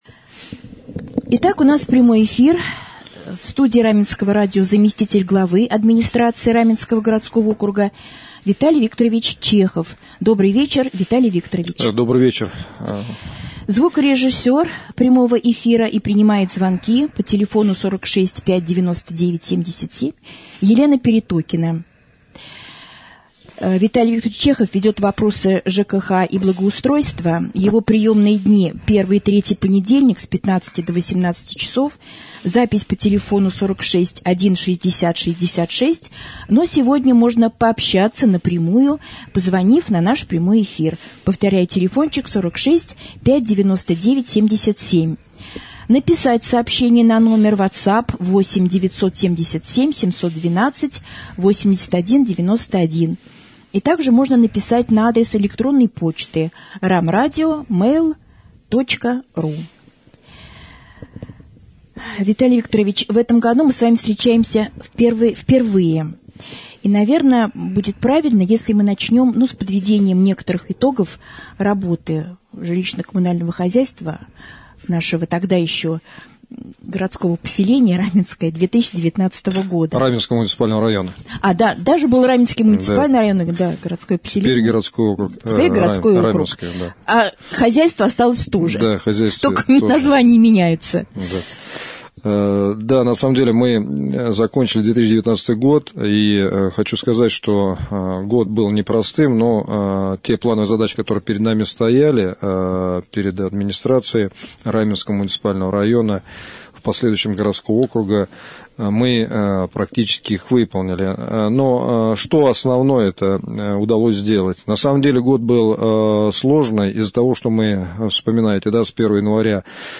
prjamoj-jefir.mp3